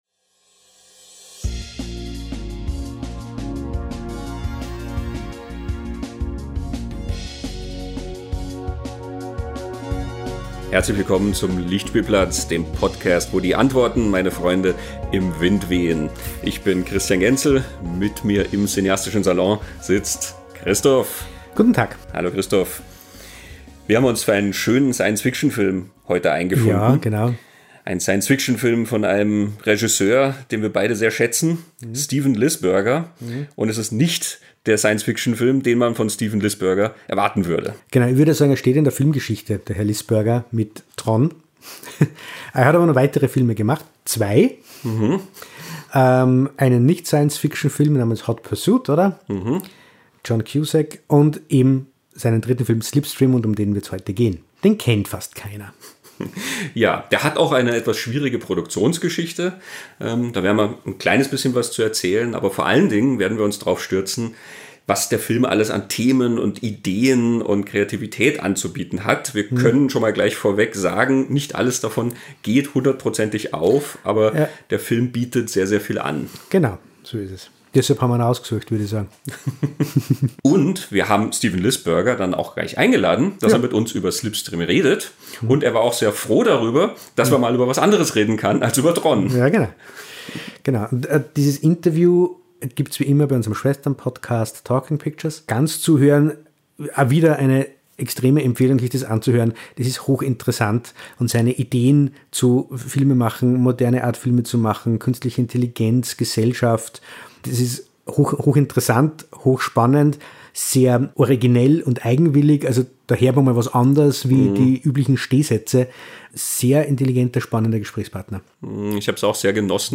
Lichtspielplatz #80: SLIPSTREAM - Künstliche Wesen jenseits von TRON (Gast: Regisseur Steven Lisberger)